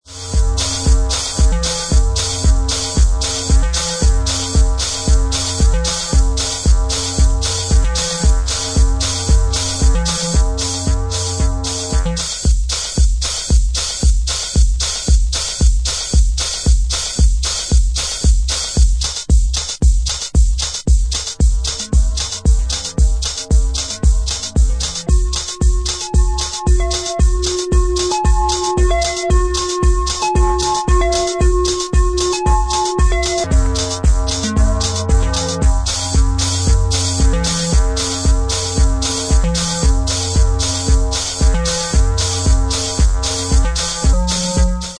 Hard edged and sick chi-house track